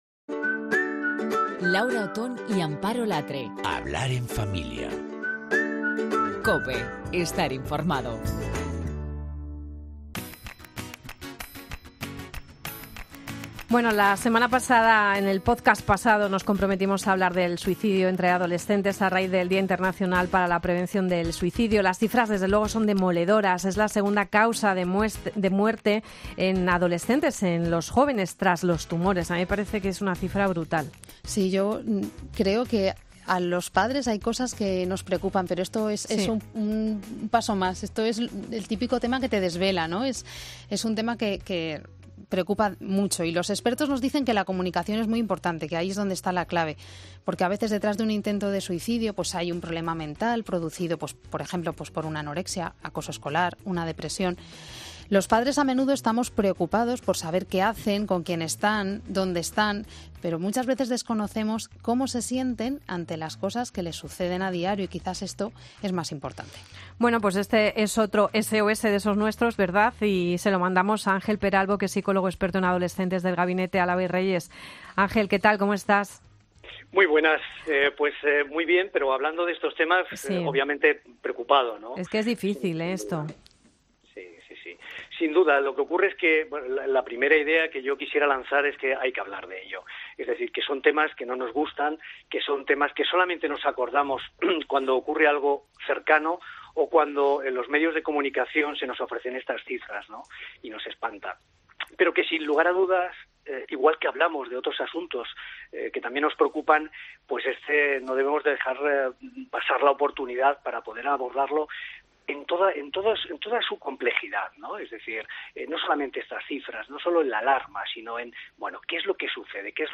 Si quieres saber más sobre este tema escucha esta charla porque la adolescencia tiende al aislamiento,  a la autolesión, hay señales que nos indican lo que pasa  hay que crear un clima de confianza, son estados depresivos que pueden pasar desapercibidos hay que observar.